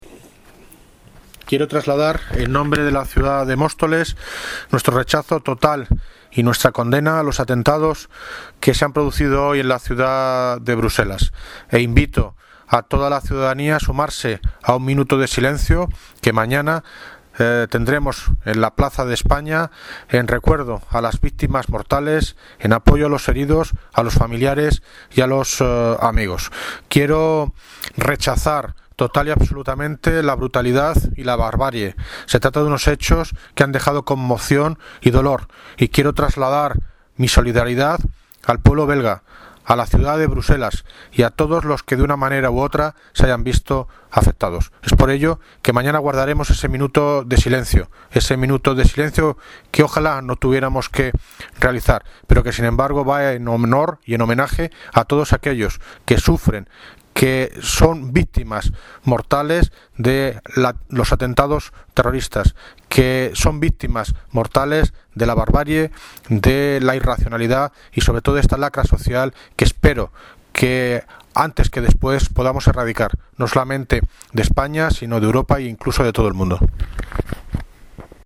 Audio - David Lucas (Alcalde de Móstoles) Condena por los atentados de Bruselas